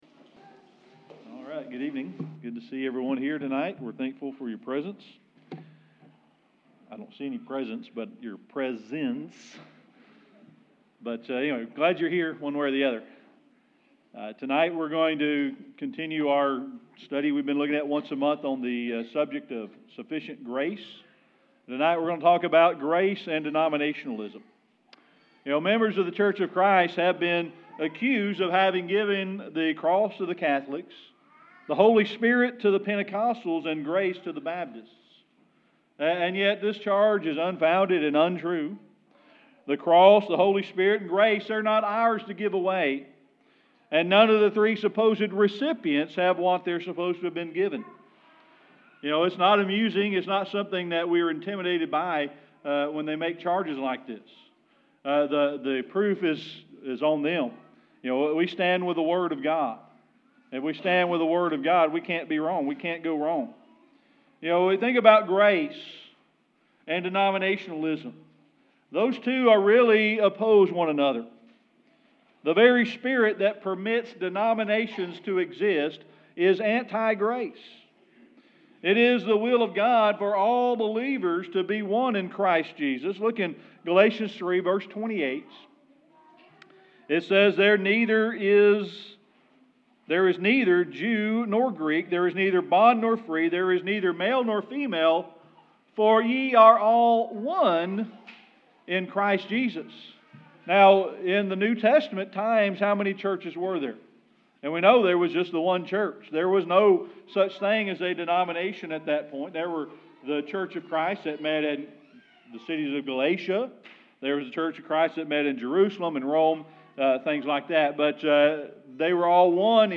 Series: Sermon Archives
Service Type: Sunday Evening Worship